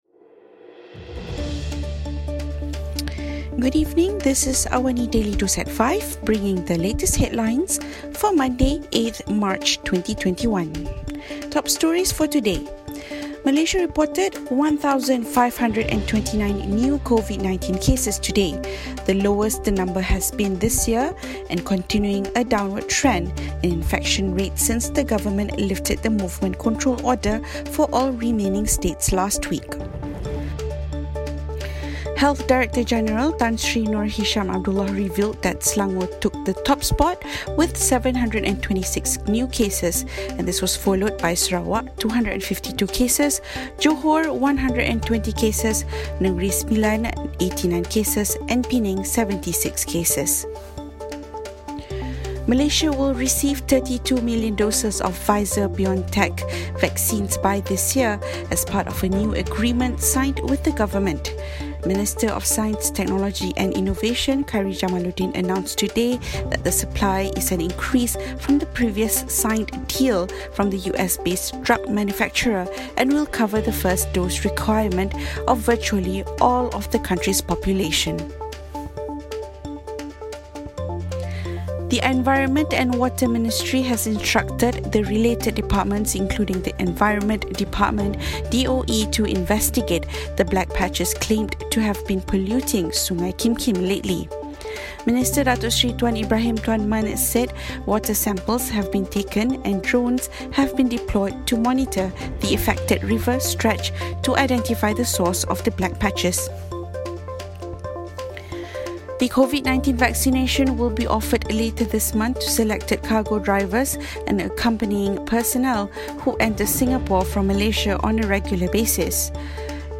Also, Anti-coup protesters gathered across Myanmar today after a trade union called for a general strike following a weekend of night raids and arrests. Listen to the top stories of the day, reporting from Astro AWANI newsroom — all in 3 minutes.